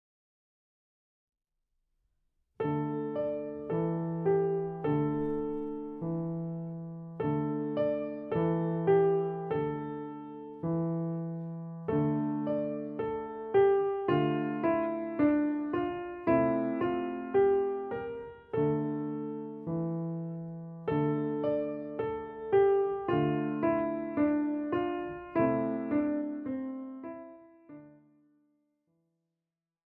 41 leichte Klavierstücke
Besetzung: Klavier